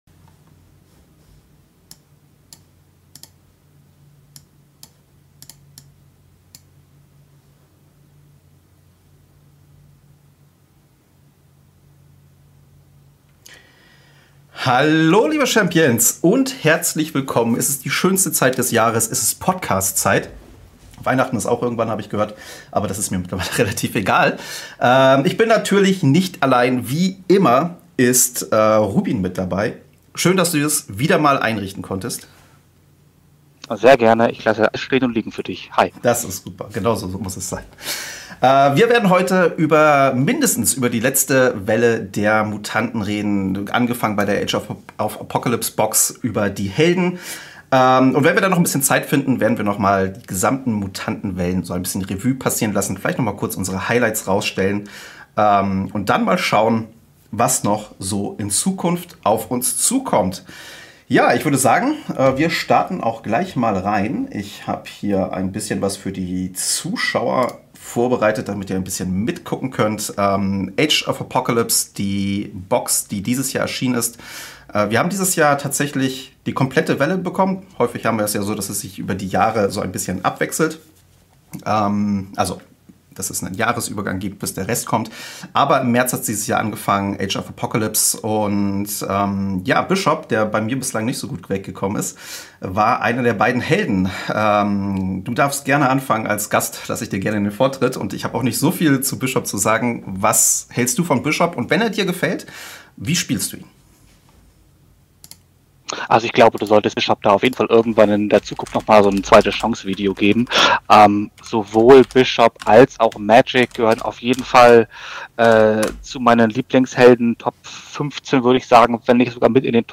Daher lade ich jedes mal bis zu drei meiner Zuschauer ein um mich über verschiedene Themen zu unterhalten.